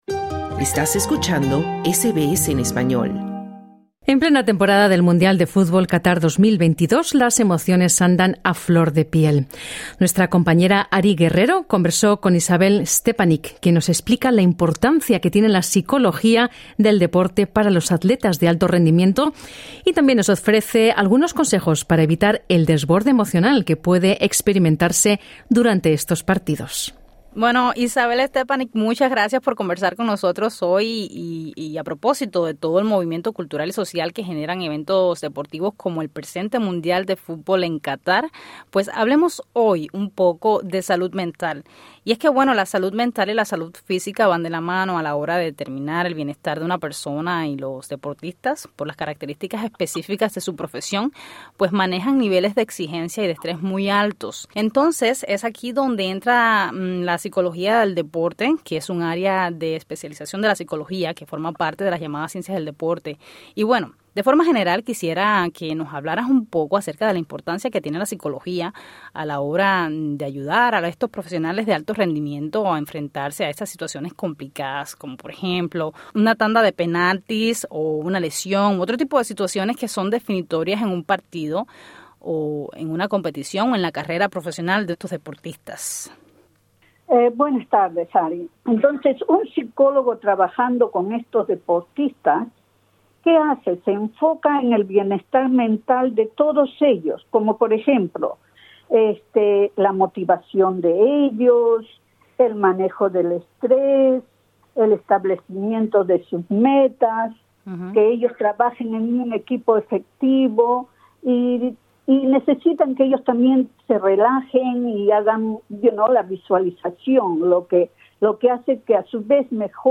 Programa en Vivo | SBS Spanish | 5 diciembre 2022